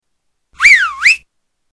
Whistling for cab